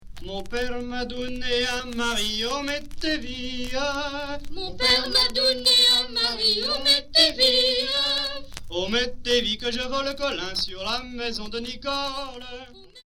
Origine : Vendée